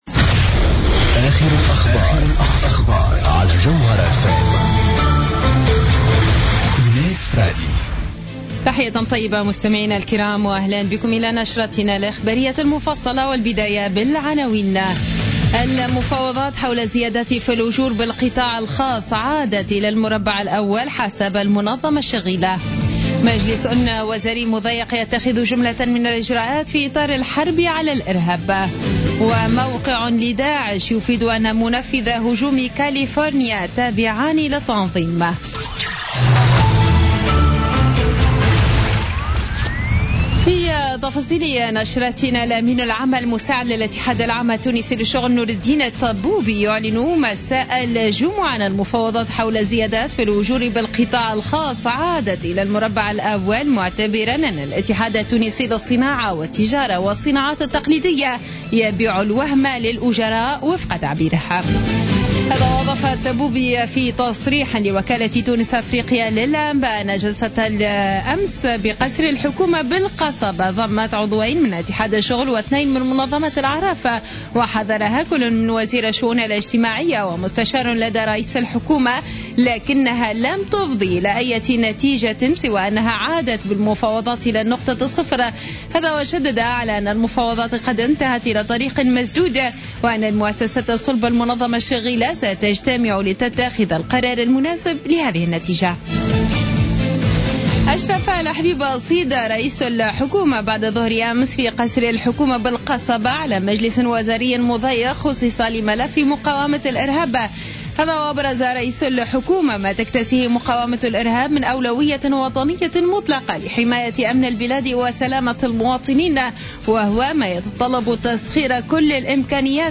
Journal Info 00:00 du samedi 05 Décembre 2015